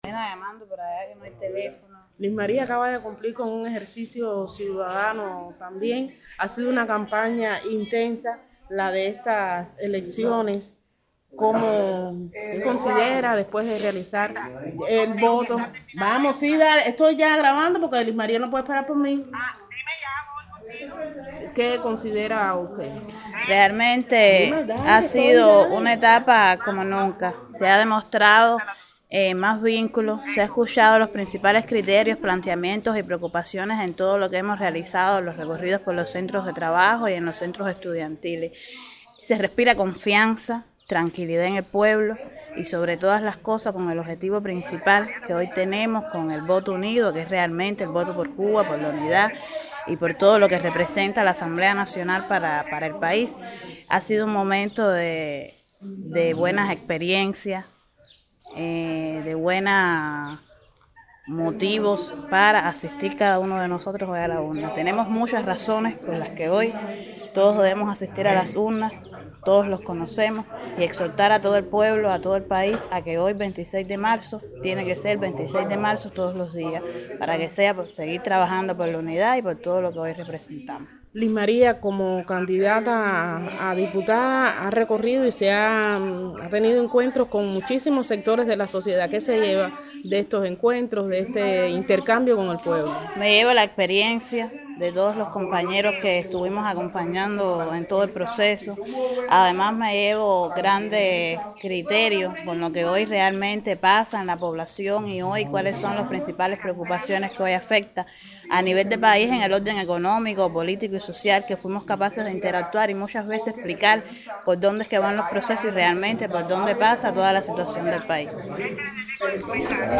Momento en que Liz María Wilson Reyes, presidenta de la Asamblea Municipal del Poder Popular en Sagua la Grande y candidata a Diputada al Parlamento por el municipio ejerce su derecho al voto.
DECLARACIONES-DE-LIZ-MARIA-WILSON-REYES-DESPUES-DE-VOTAR.mp3